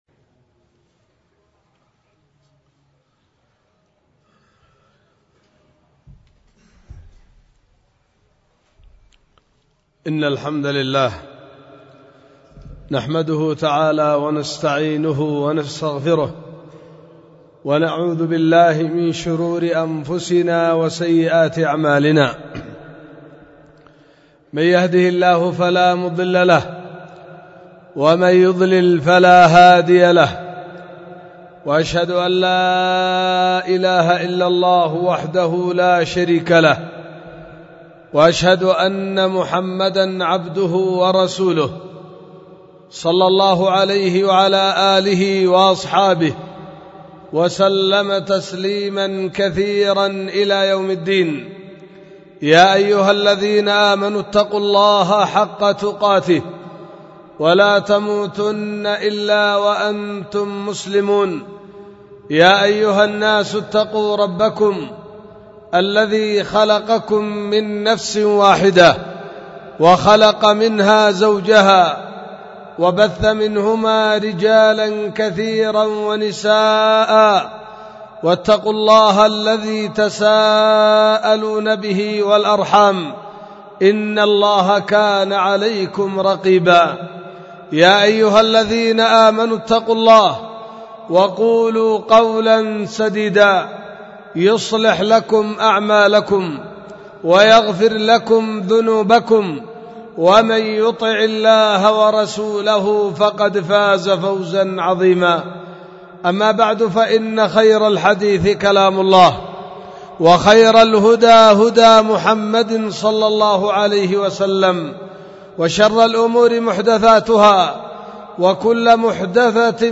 خطبة ألقيت في 13 جمادى الآخرة 1444 هجرية في دار الحديث بوادي بنا – السدة – إب – اليمن